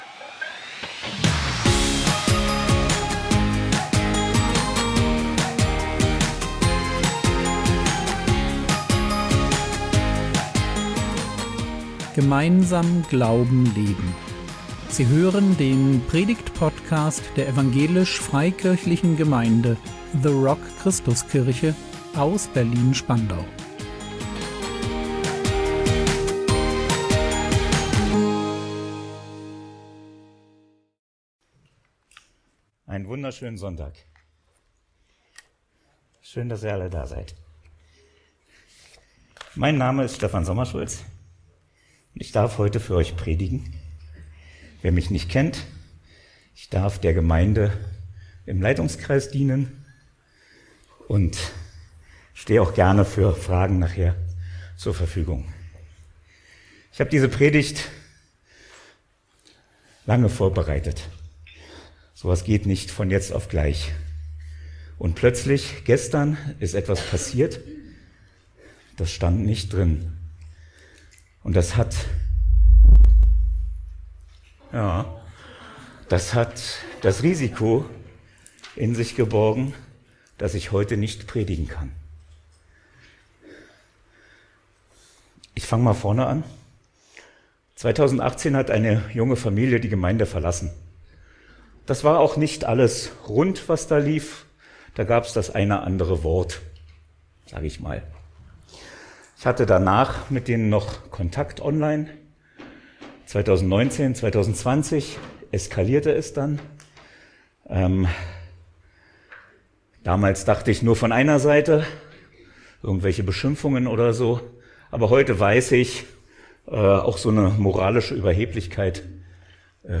Eskalation von Vergebung und Gnade | 09.06.2024 ~ Predigt Podcast der EFG The Rock Christuskirche Berlin Podcast